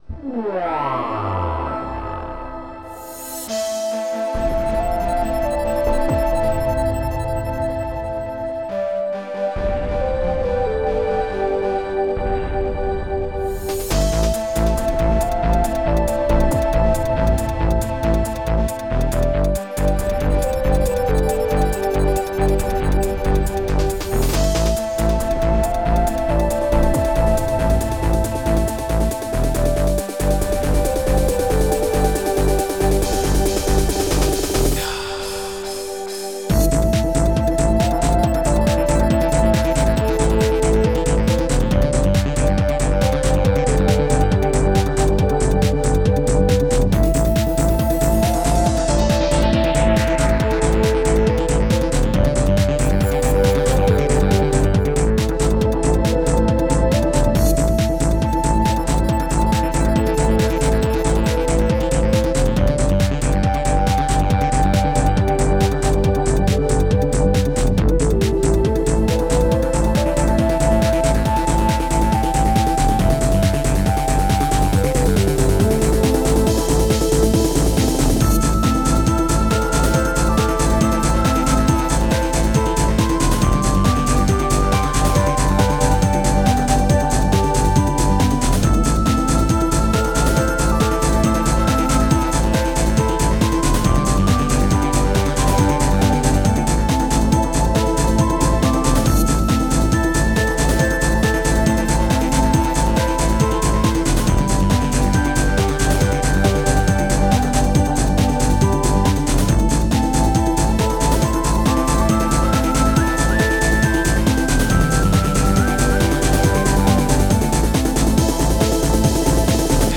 xm (FastTracker 2 v1.04)
old irish national
Snare_80
E-Guitar 09
16-Beat Pop L2 / 112